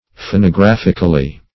Search Result for " phonographically" : The Collaborative International Dictionary of English v.0.48: Phonographically \Pho`no*graph"ic*al*ly\, adv. In a phonographic manner; by means of phonograph.